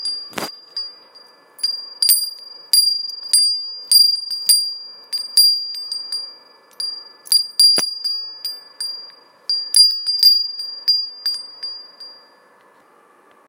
Zvonkohra s retiazkou sova
Jemné, vysoké tóny, ktoré pripomínajú spev vtákov, majú na svedomí kovové zvonkohry.
litinovy-zvonek-zvuk.m4a